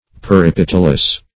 Meaning of peripetalous. peripetalous synonyms, pronunciation, spelling and more from Free Dictionary.